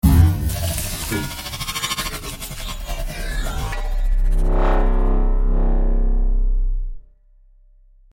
• Качество: 128, Stereo
Прикольная мелодия смс